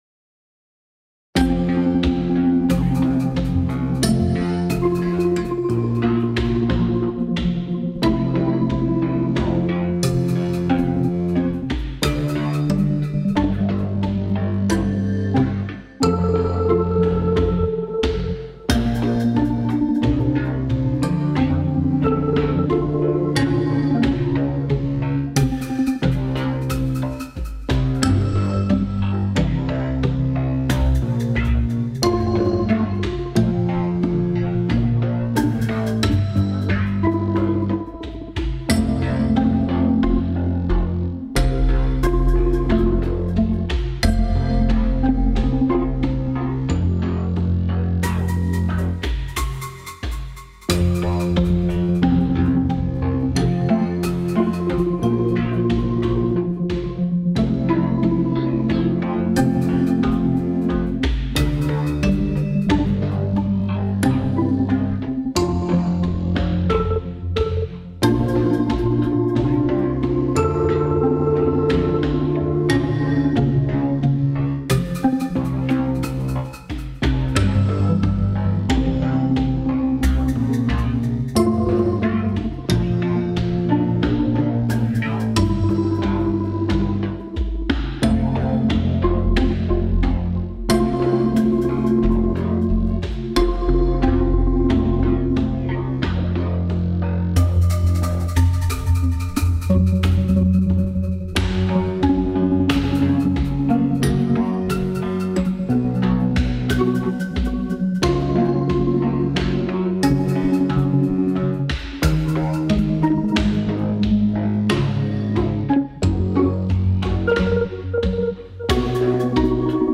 Running all 180 Beats p Minute